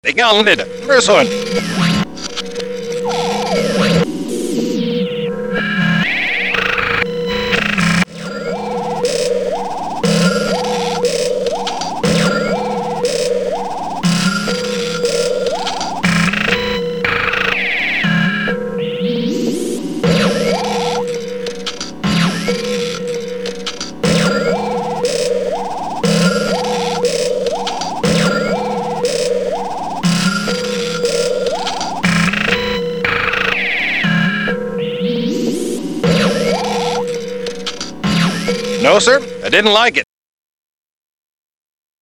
computer & samples
drums & guitar samples.